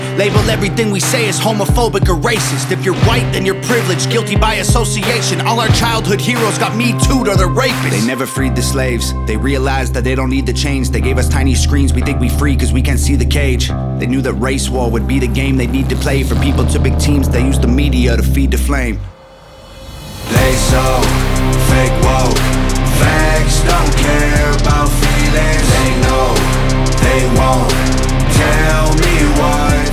• Hip-Hop/Rap
The song begins with an isolated clean electric guitar.